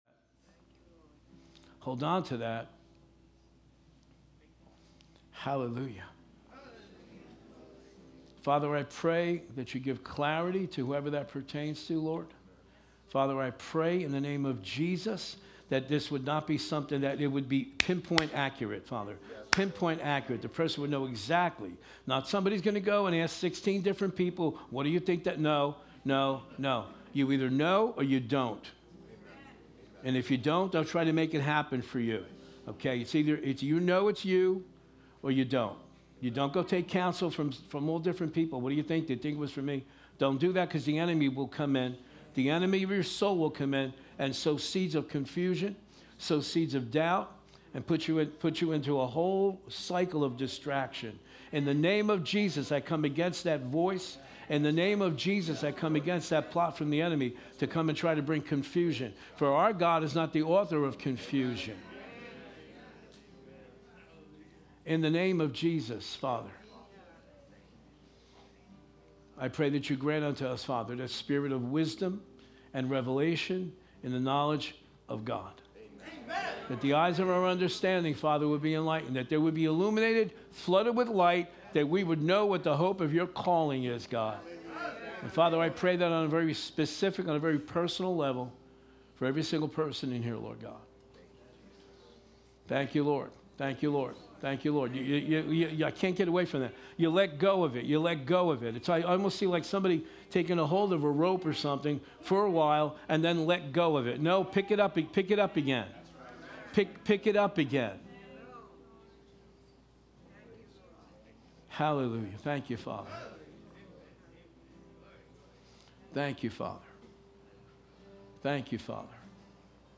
Saturday 7pm Service